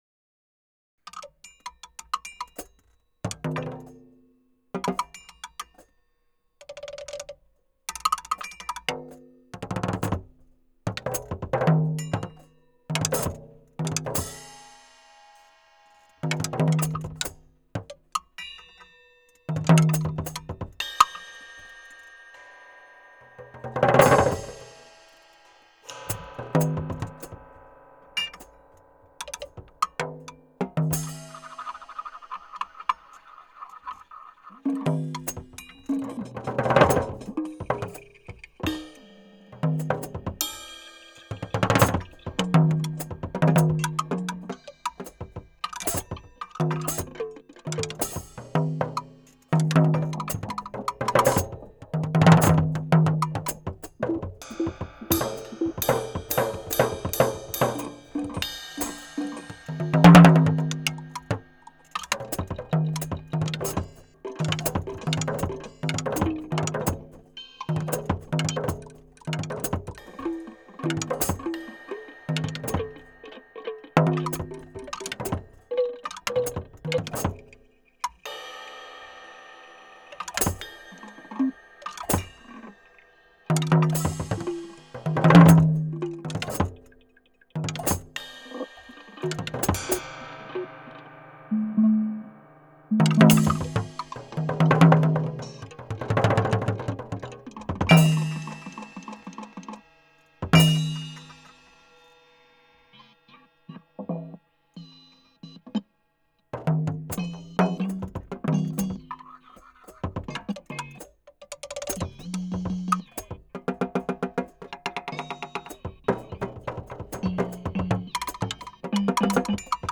Augmenting Percussion with Electronics in Improvised Music Performance
Keywords: Improvised music, improvisation, live electronics, digital electronics, analogue electronics, hybrid instrument, electroacoustic music, solo percussion, Human Computer Interaction
3.  Bullfrogs 8.42 Solo.wav